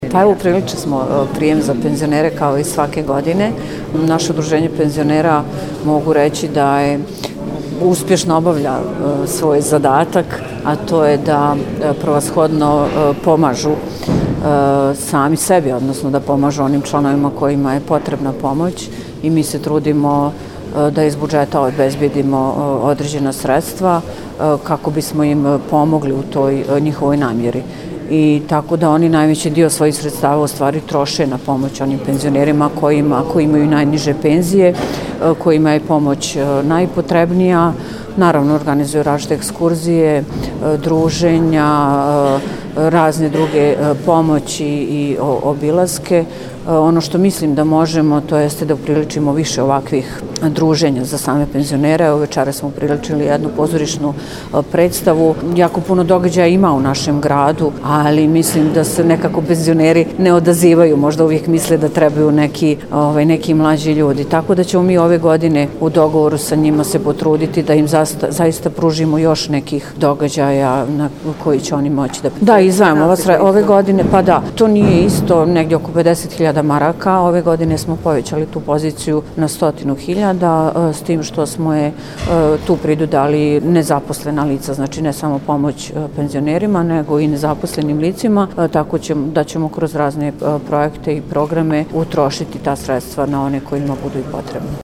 Načelnik opštine Mrkonjić Grad, Divna Aničić rekla je da su na ovaj način organizovali druženje za penzionere i da će nastojati da ovakvih i sličnih događaja bude još više. Ona je navela da se u budžetu opštine na godišnjem nivou izdvoji oko 50.000,00 KM za Udruženje penzionera i da oni na razne načine pomažu članovima kojima je to potrebno.
izjavu